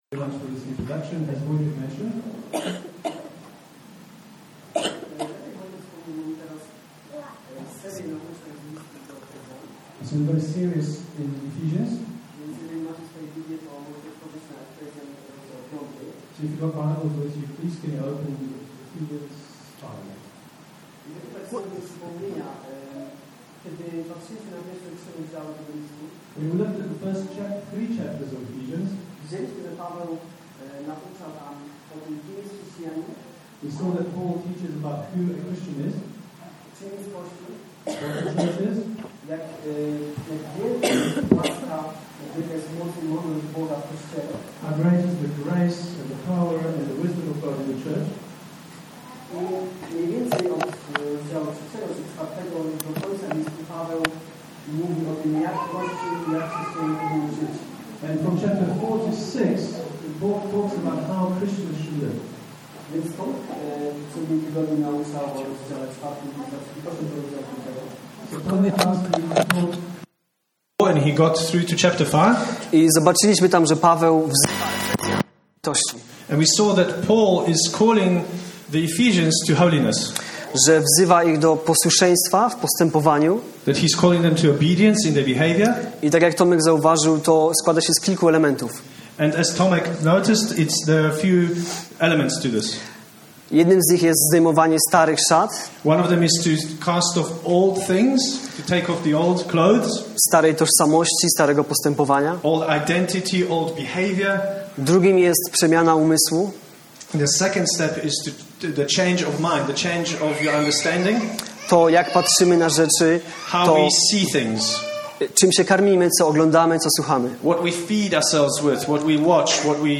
Unfortunately, there are some issues with the audio in the first 1:20